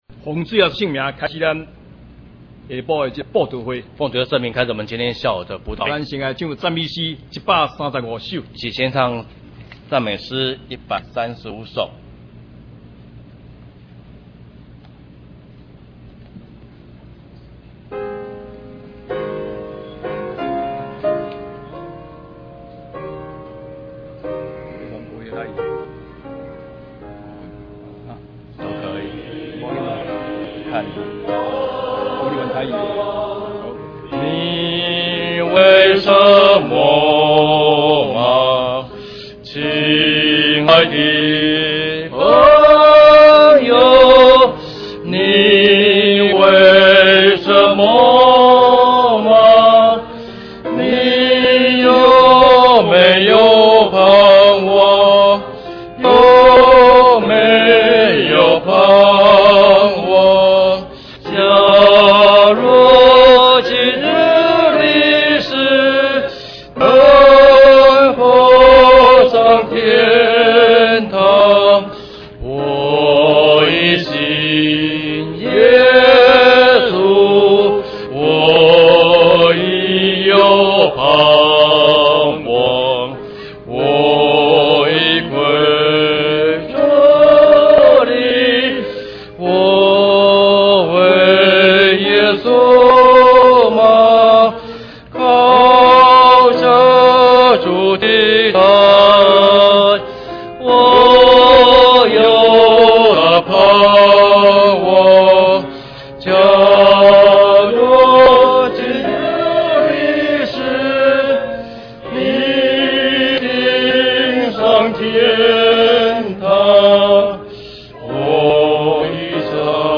月佈道
獻詩：撒母耳詩班